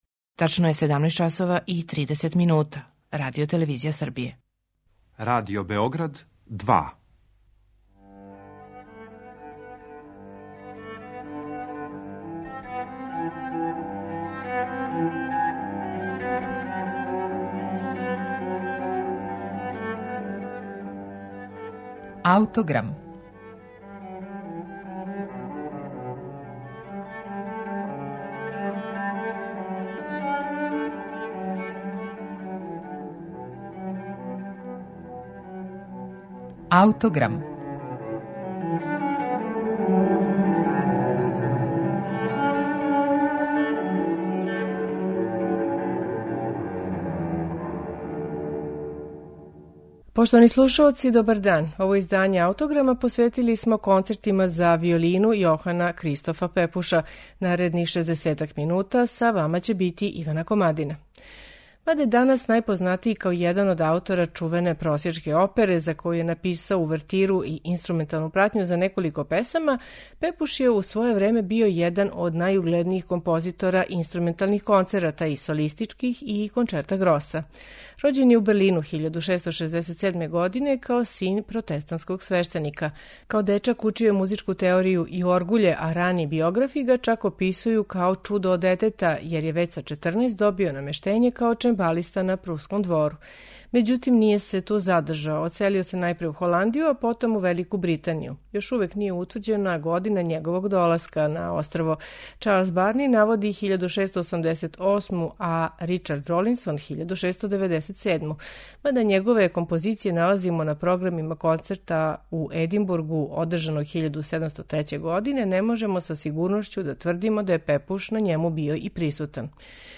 Концерти за виолину Јохана Кристофа Пепуша
Данашњи Аутограм посветили смо Пепушевим концертима за виолину. Први од њих, Concerto grosso за виолину и гудаче у Бe-дуру је, уједно, први сачувани инструментални концерт настао у Великој Британији. Пепушове концерте слушаћете у интерпретацији оркестра Harmonious Society of Tickle-Fiddle Gentlemen.